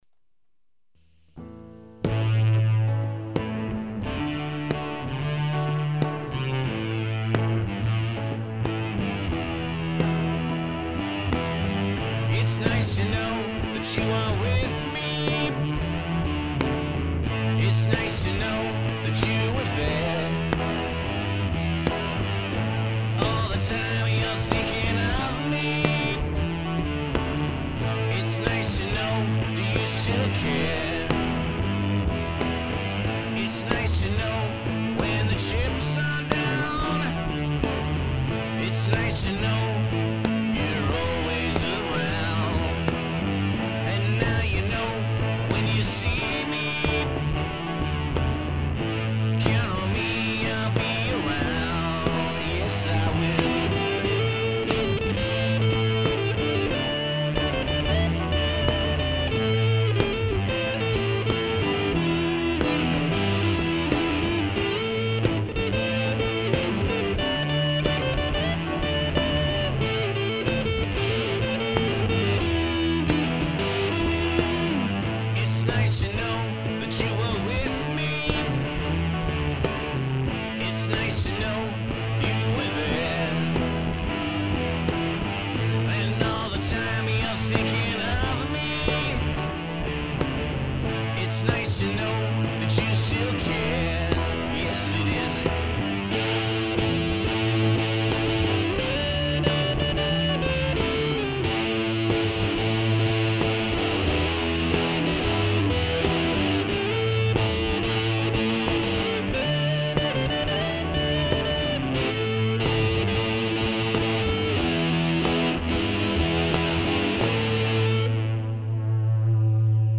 All instruments are played by me without any click tracks.
That's why they sound so rough.